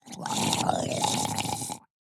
Minecraft Version Minecraft Version snapshot Latest Release | Latest Snapshot snapshot / assets / minecraft / sounds / mob / drowned / idle3.ogg Compare With Compare With Latest Release | Latest Snapshot